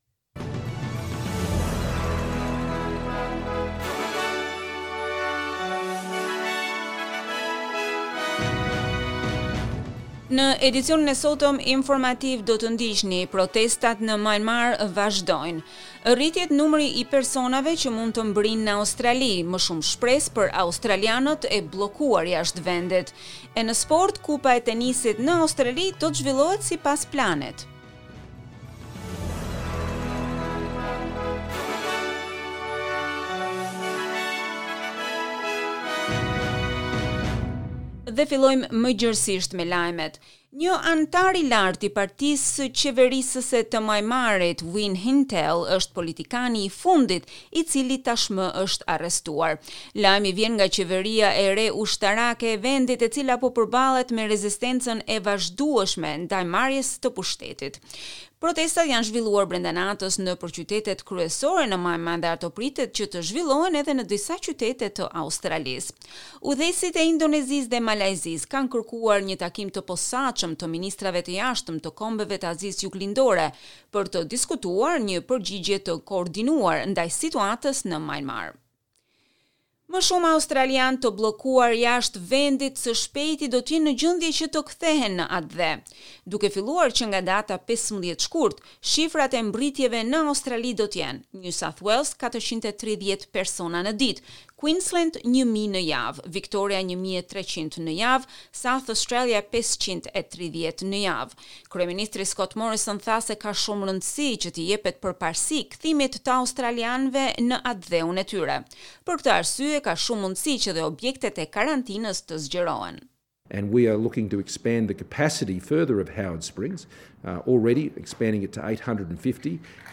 SBS News Bulletin in Albanian - 6 February 2021